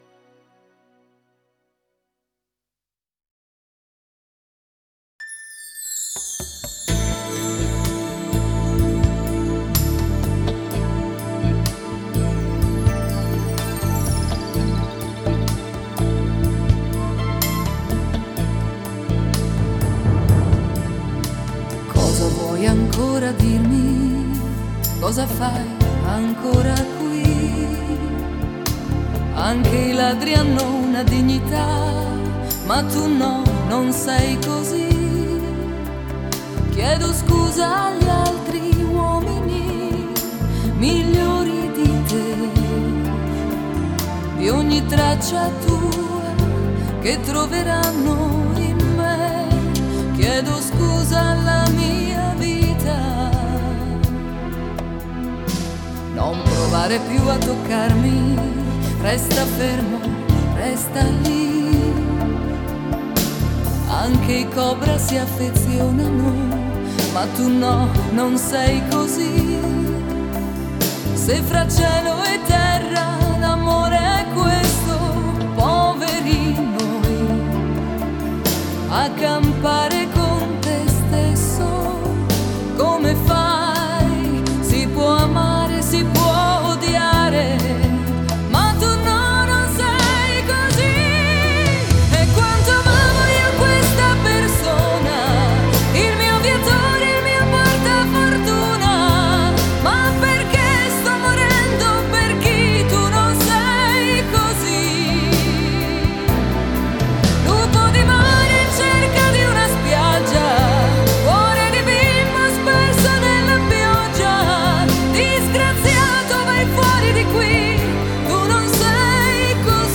Жанр: Italo Pop